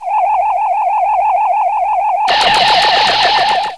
MISSILE.WAV